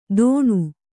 ♪ dōṇu